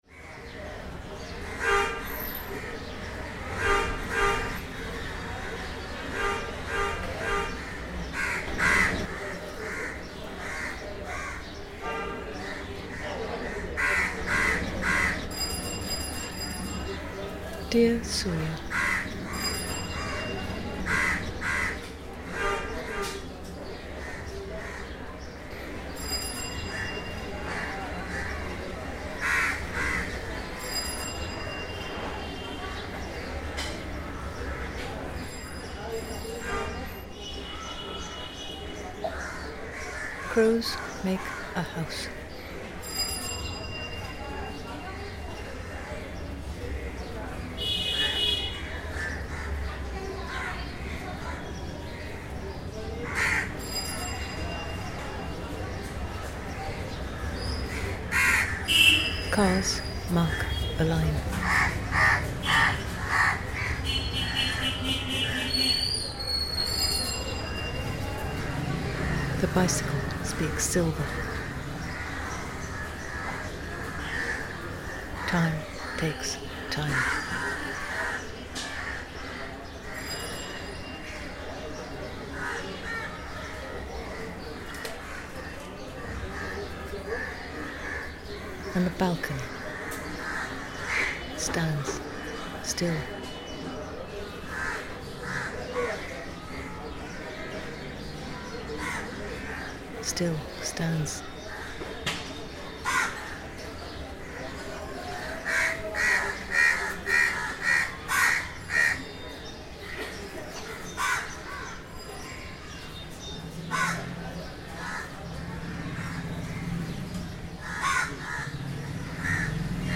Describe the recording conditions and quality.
Kolkata balcony recording reimagined